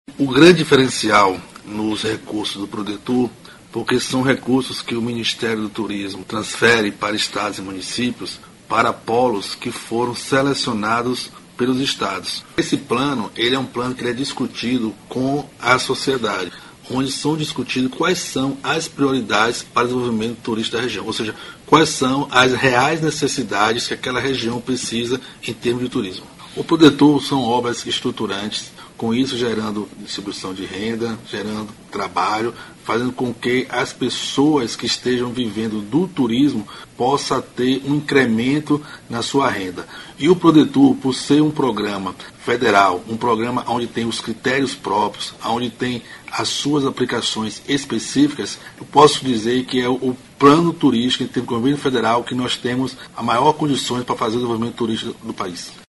aqui para ouvir declaração do secretário Carlos Henrique Sobral sobre a importância de investimentos do Prodetur.